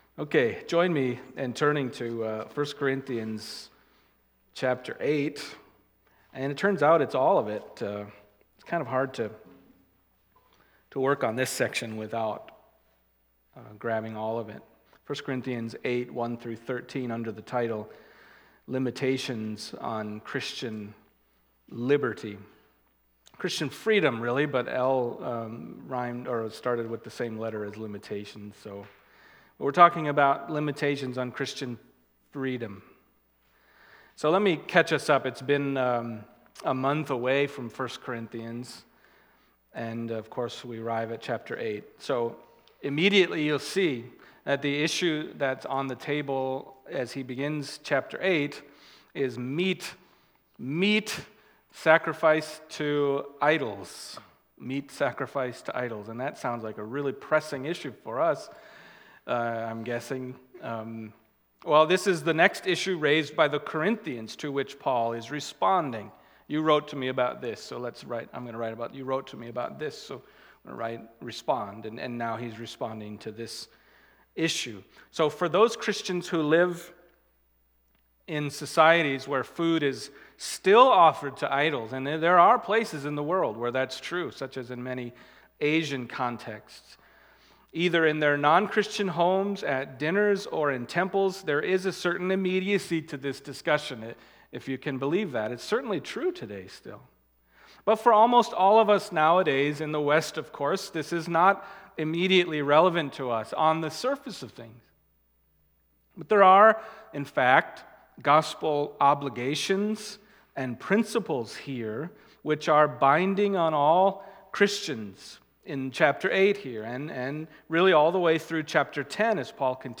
Passage: 1 Corinthians 8:1-13 Service Type: Sunday Morning